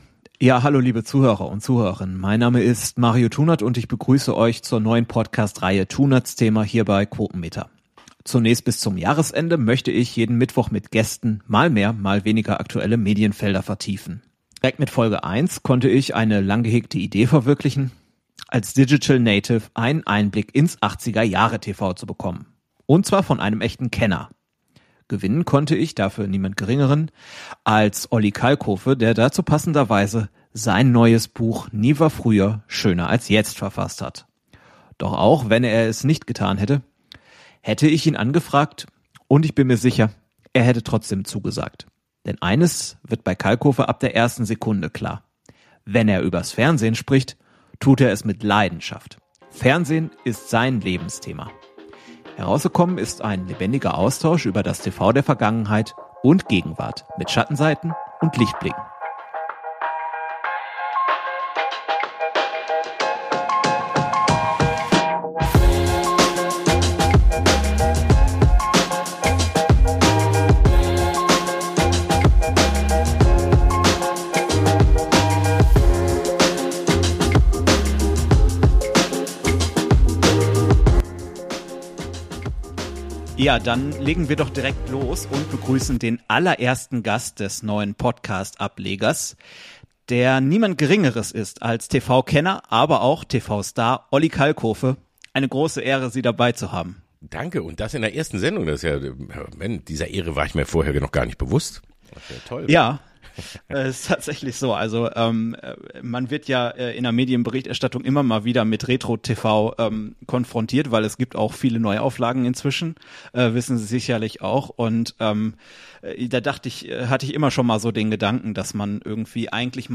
Beispielsweise dann, wenn Kalkofe �ber die p�dagogisch belehrende Trockenheit fr�herer Dekaden spricht, die dennoch legend�re Kult-Sendungen und Showmaster hervorgebracht hat. Aber auch in Passagen, in denen das Gespr�ch auf die aktuelle Innovationsarmut kommt, welche die Showlandschaft trotz seltener Lichtblicke durchzieht.